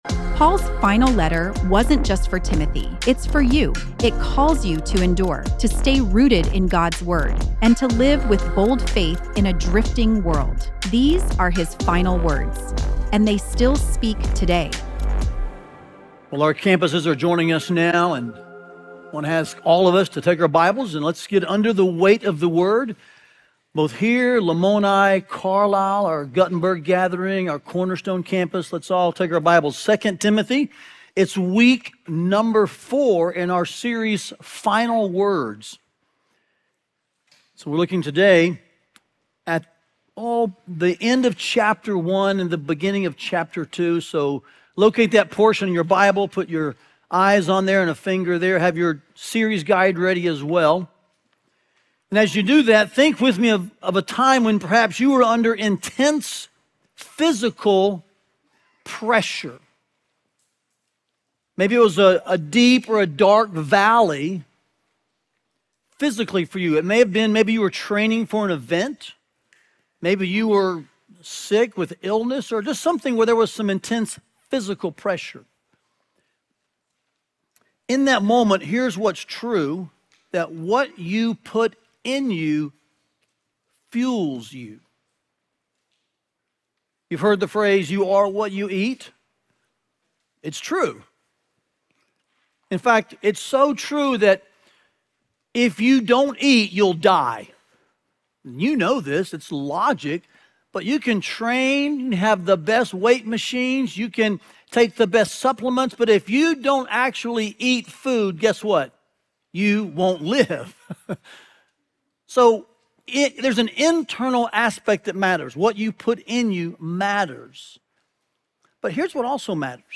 The Holy Spirit shapes me for gospel suffering through gospel relationships. Listen to the latest sermon from our 2 Timothy series, Final Words, and learn more about the series here.